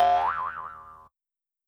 Spring_rebound_sfx.wav